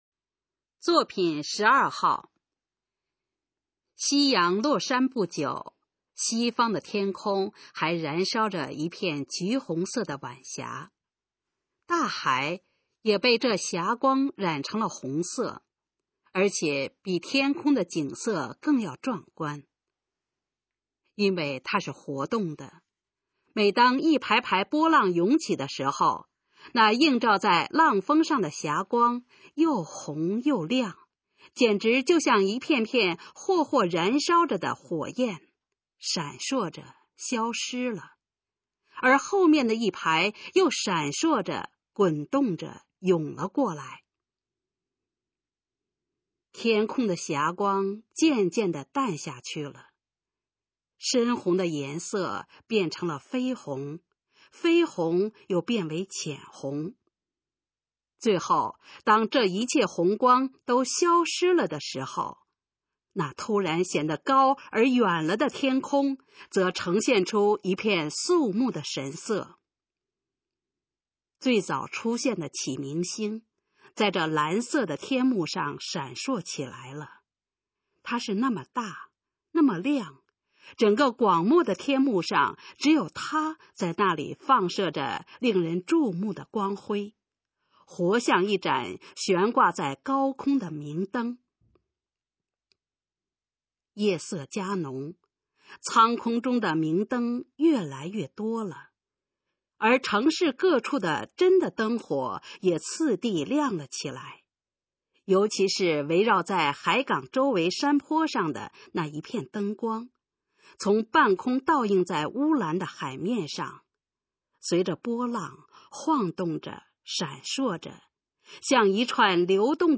《海滨仲夏夜》示范朗读_水平测试（等级考试）用60篇朗读作品范读